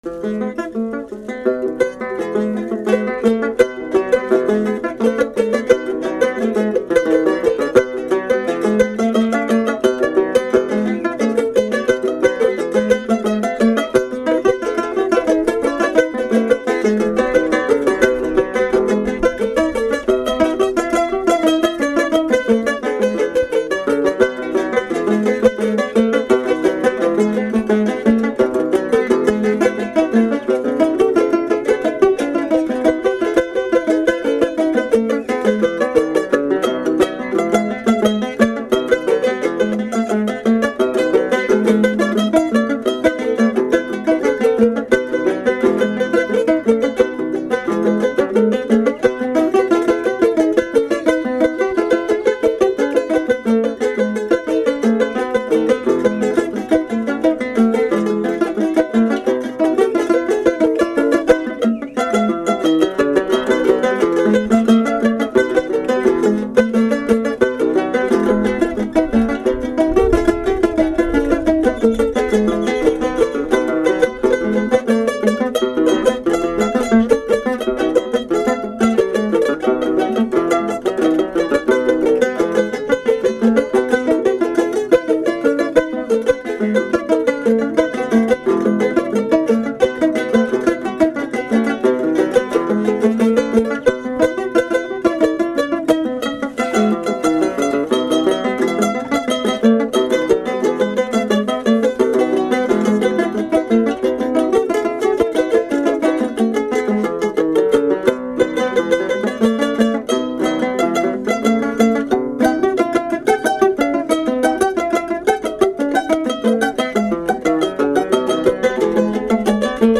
Maestros de la guitarra de son
guitarra de son
requinto